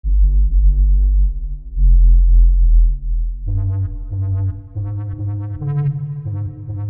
环境音的回声效果
描述：环境音的回声效果。 一个拉伸或低调并混响的样本。
标签： 配音 怪异 dubstep 回声 立体声 噪声 Trance 循环 音效
声道立体声